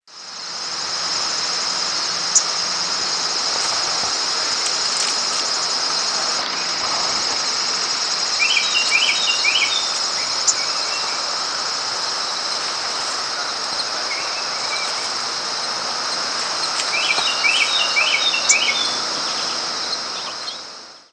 Wilson's Warbler diurnal
Wilson's Warbler diurnal flight calls
Bird in flight.